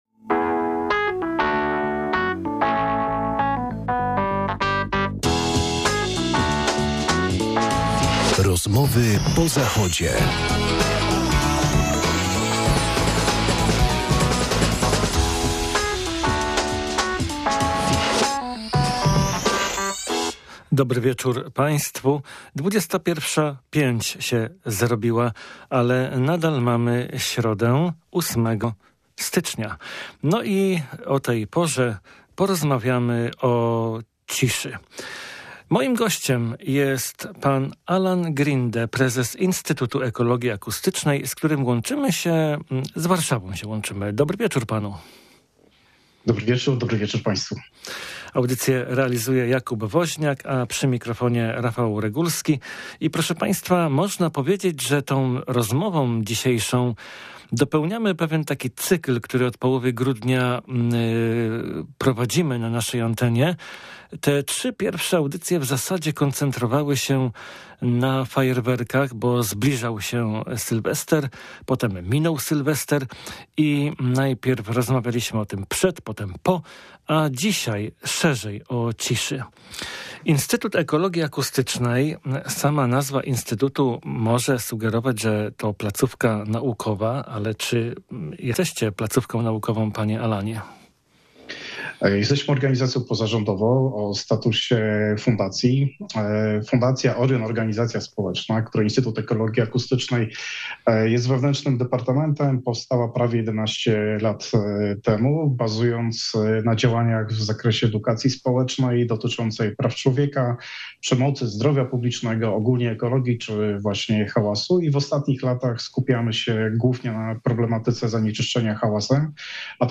Z ciekawymi obserwacjami do audycji włączyli się też nasi słuchacze.